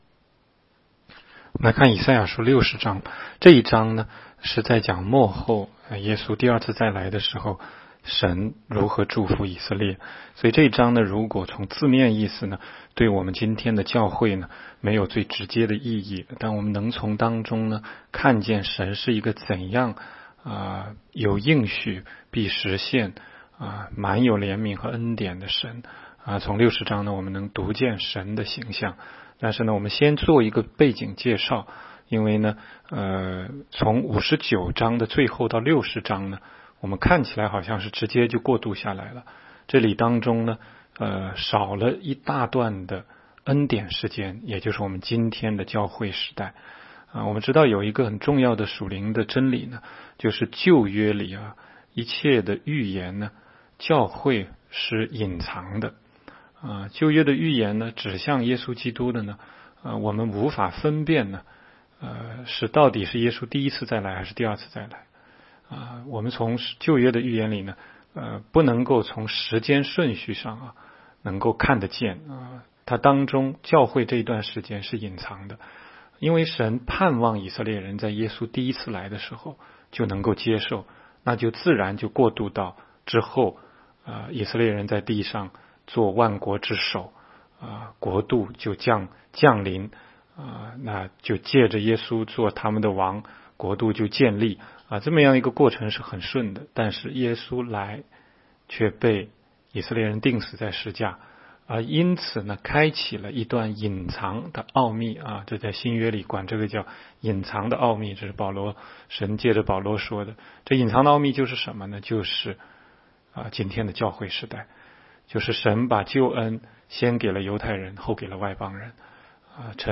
16街讲道录音 - 每日读经 -《以赛亚书》60章